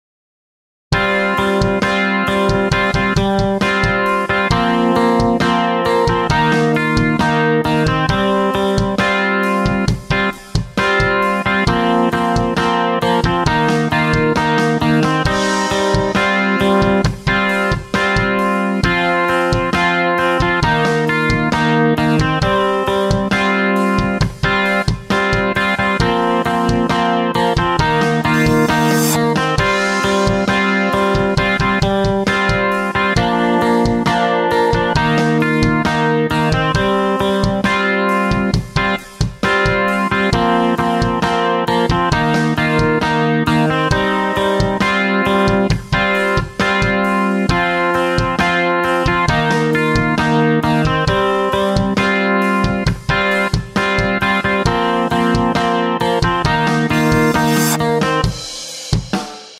Chorus Electric Guitar